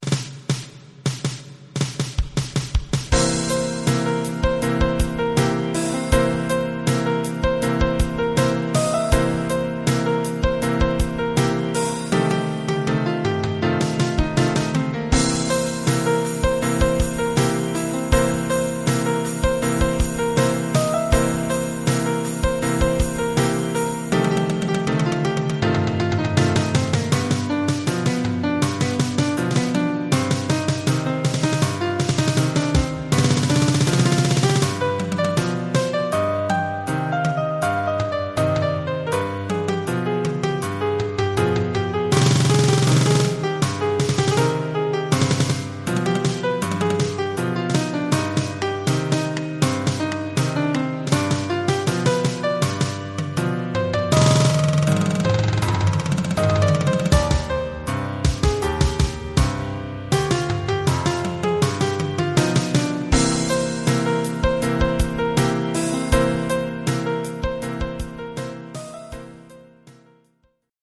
Collection : Percussions (batterie)
Oeuvre pour batterie et piano.